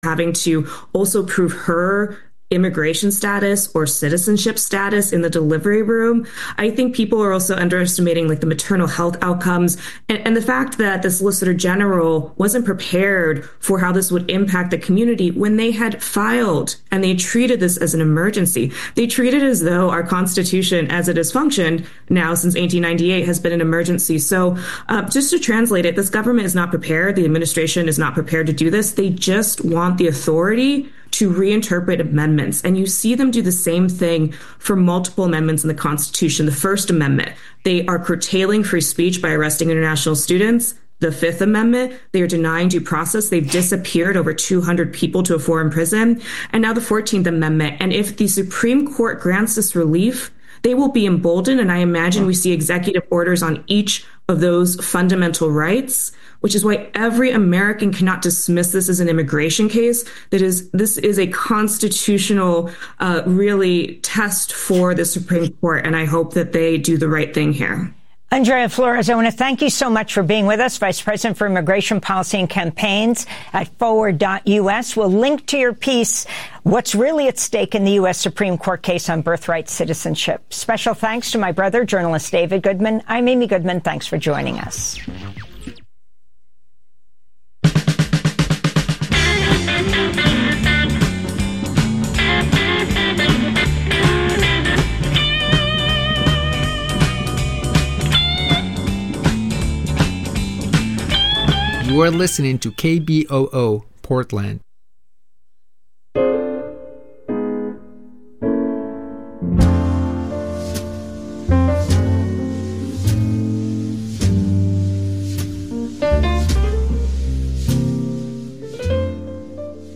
Non-corporate, community-powered, local, national and international news